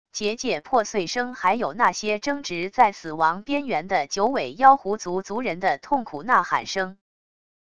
结界破碎声还有那些争执在死亡边缘的九尾妖狐族族人的痛苦呐喊声wav音频